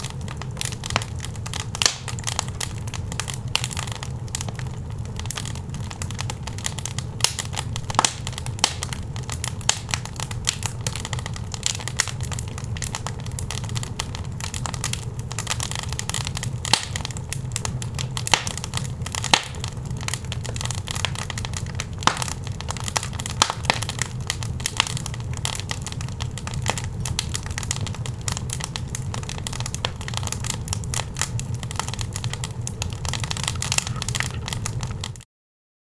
Fire Oven Wood Burning Sound
household